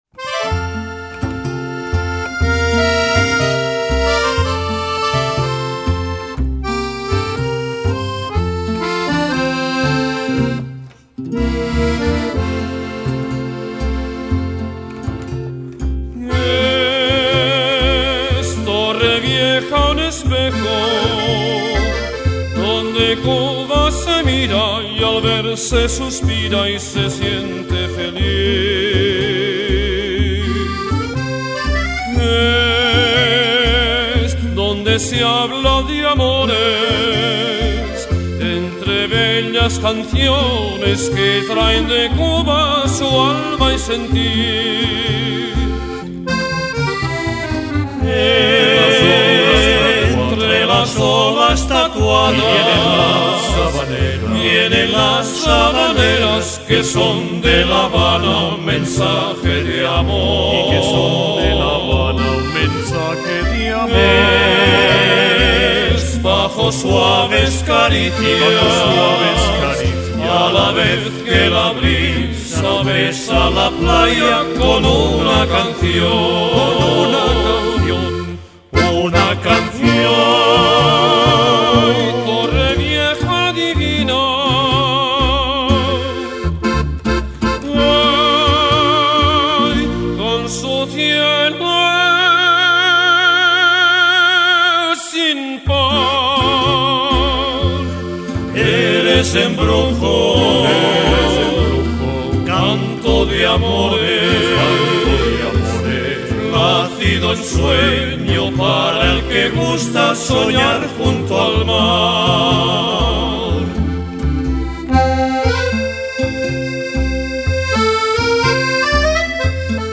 Habaneras